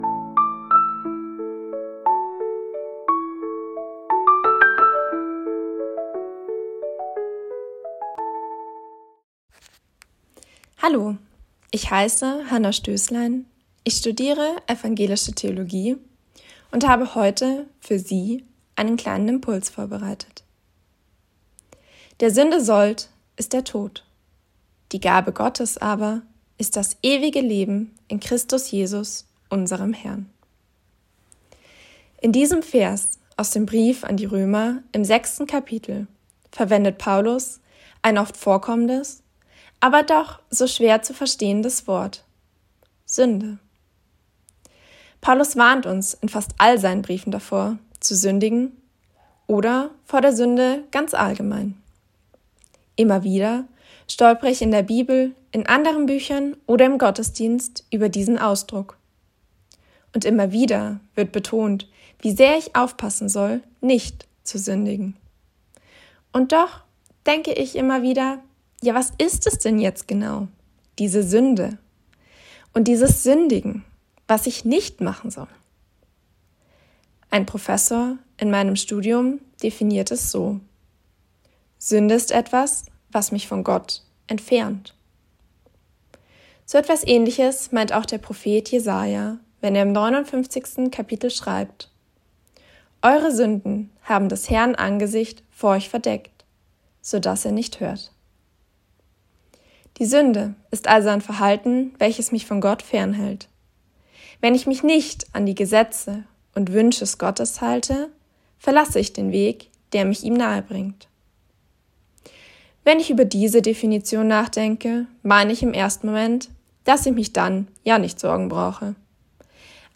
Losungsandacht für Mittwoch, 05.03.2025